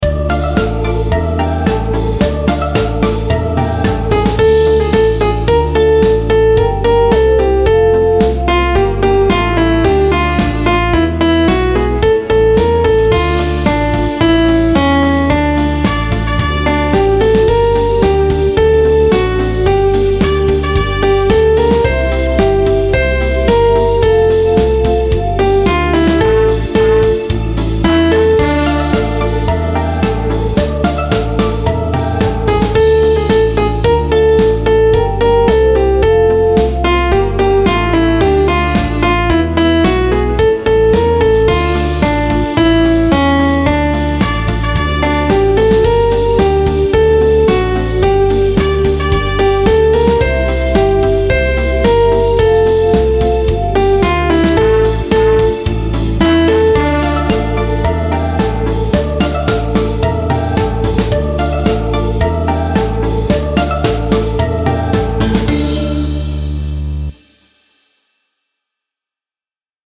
• Жанр: Детские песни
Слушать Минус